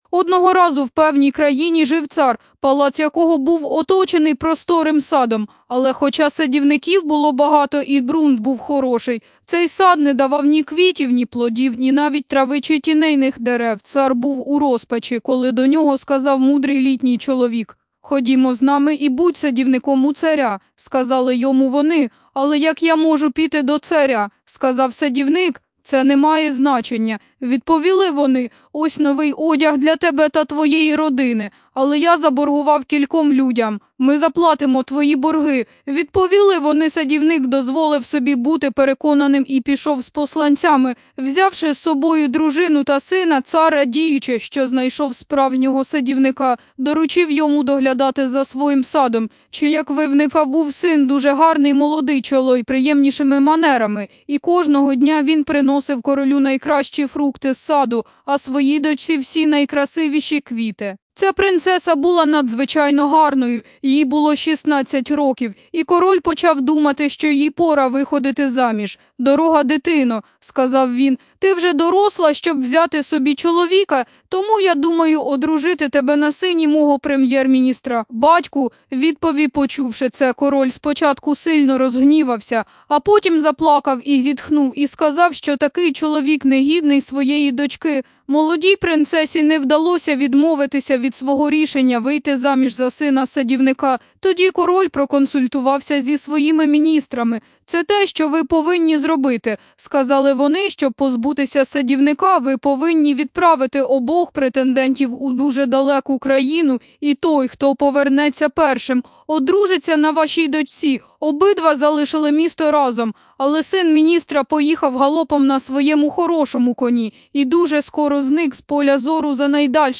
The_Blue_Fairy_Book_sample.mp3